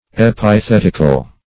Search Result for " epithetical" : The Collaborative International Dictionary of English v.0.48: Epithetic \Ep`i*thet"ic\, Epithetical \Ep`i*thet"ic*al\, a. [Gr.